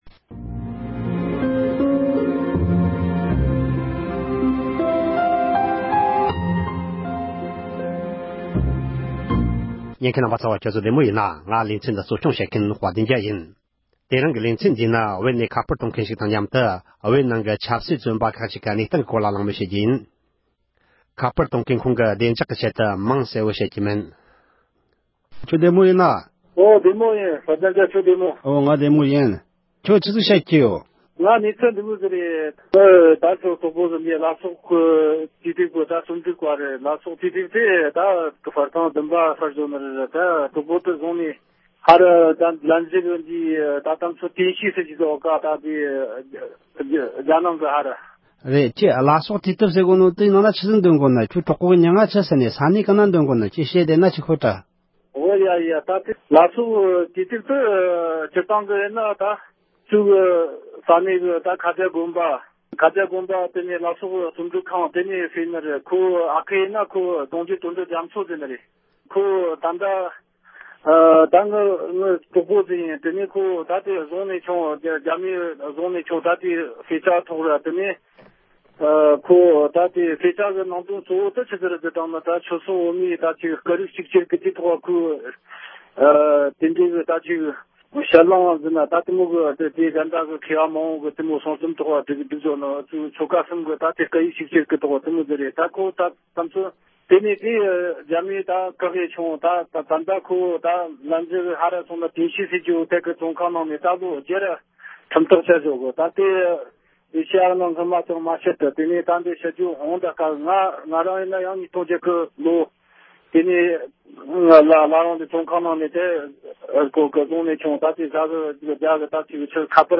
གླེང་མོལ་བྱས་པར་ཉན་རོགས་གནོངས།